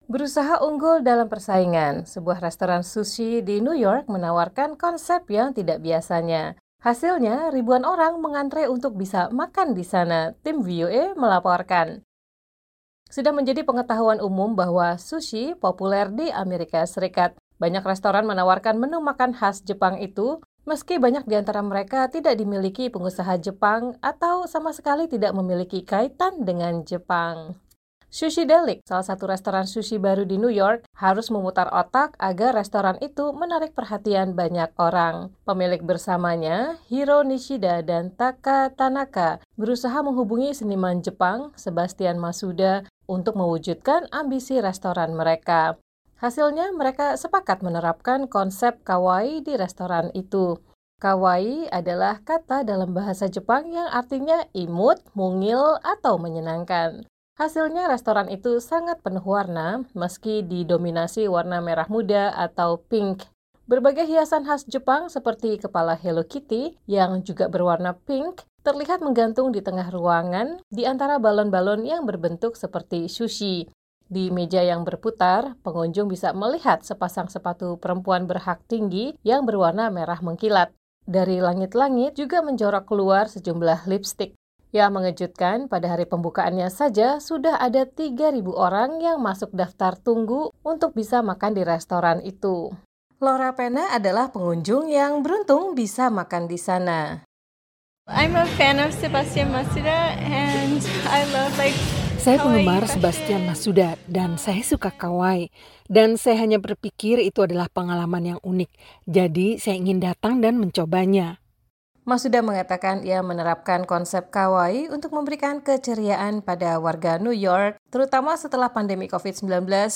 Tim VOA melaporkan.